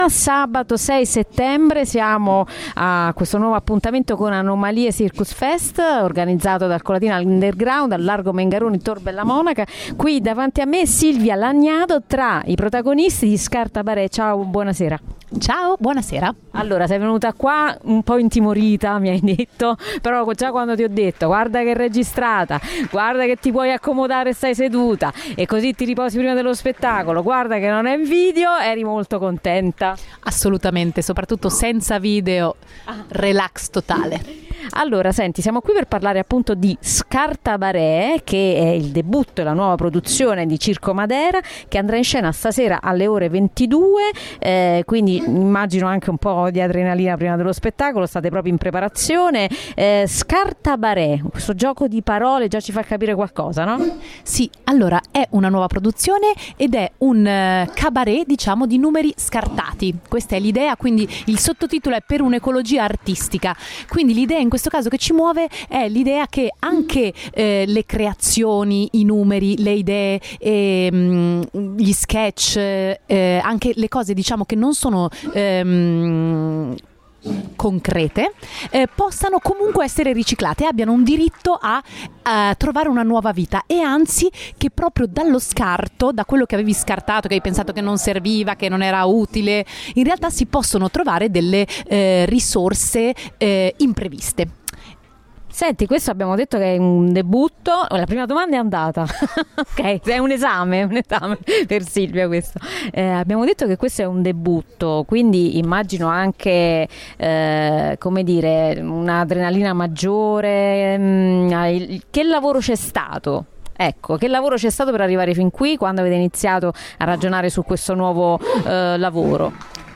Radio città aperta sarà presente con la sua radio anomala.